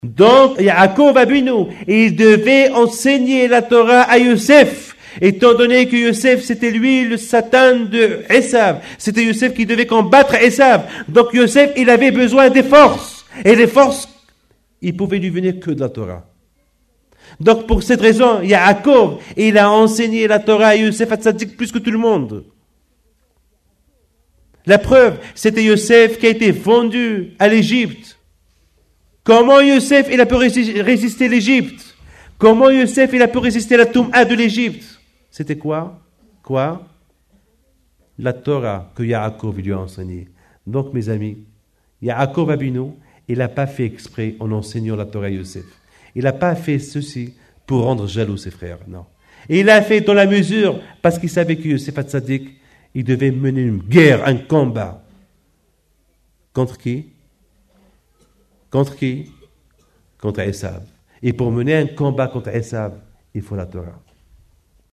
Shiour
donné à l’école OZAR HATORAH à Sarcelles